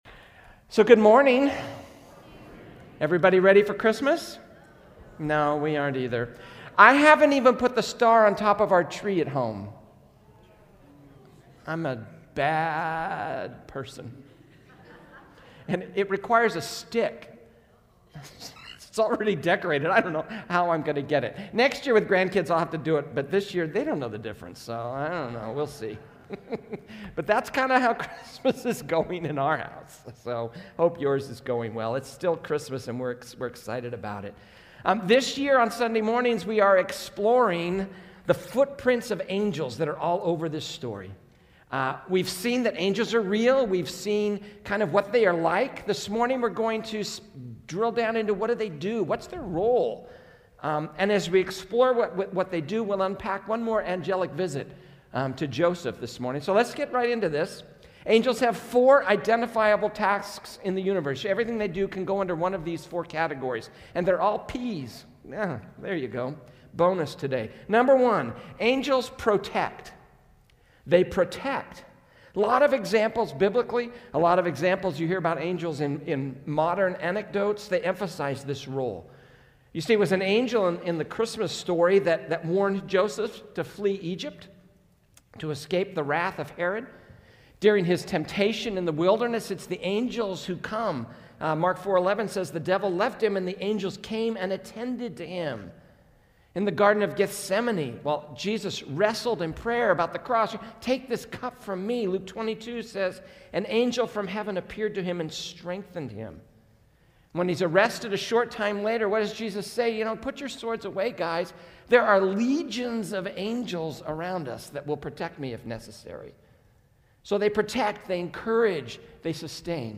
A message from the series "The Unexpected King."